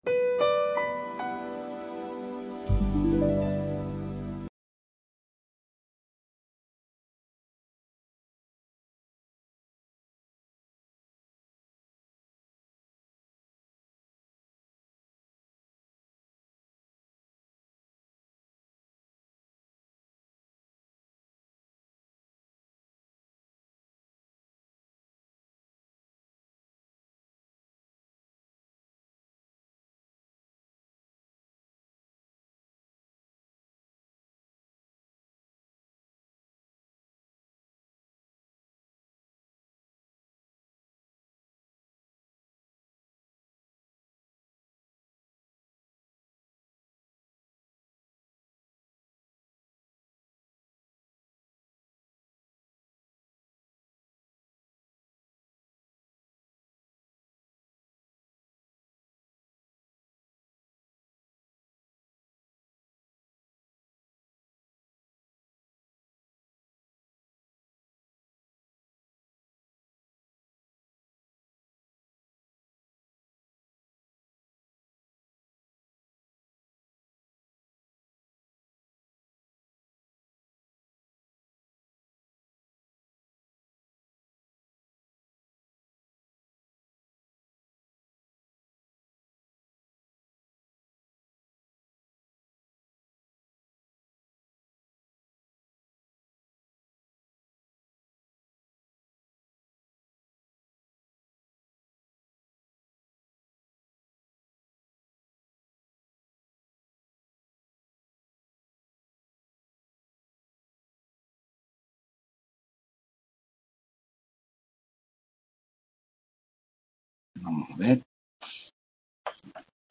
Primera Tutoría de Fundamentos del Trabajo Social